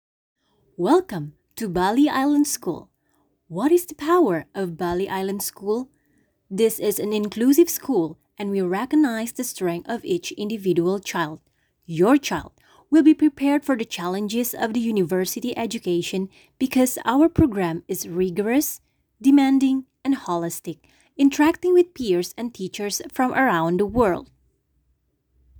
Powerful yet Kind. Dignified but approachable.